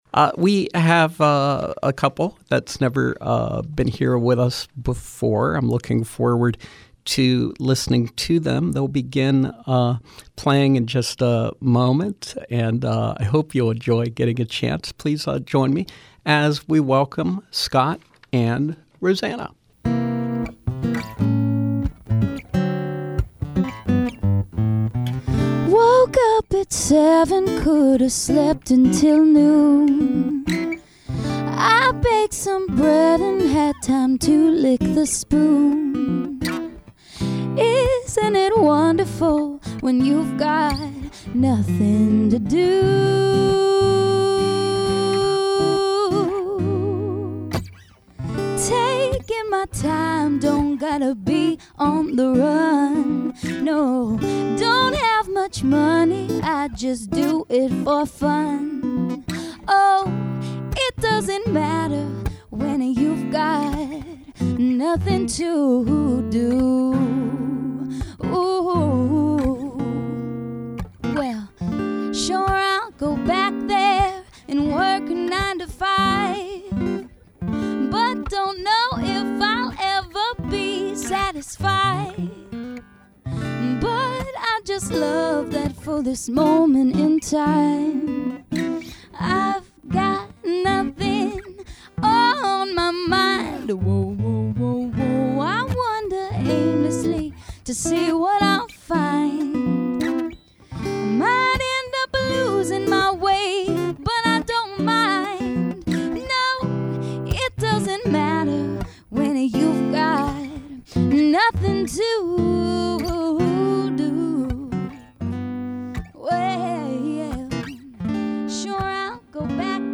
Live music with singer/songwriter duo